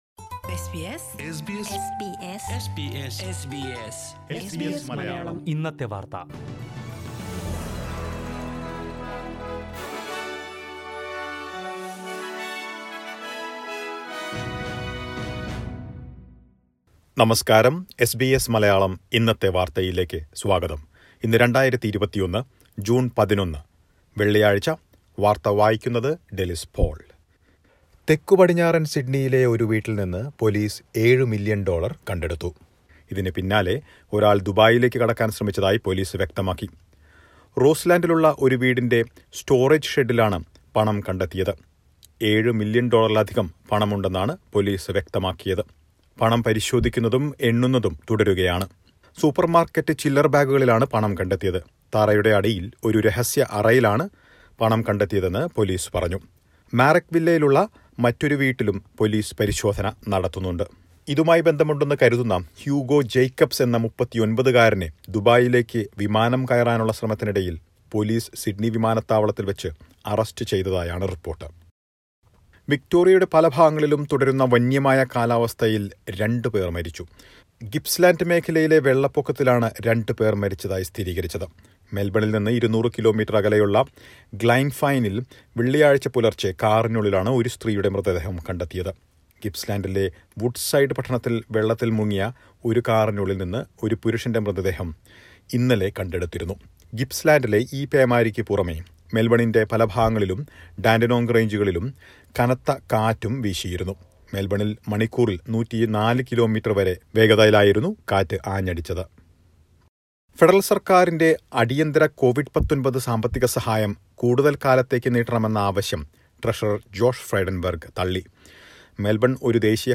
news_mlm_1106editednew.mp3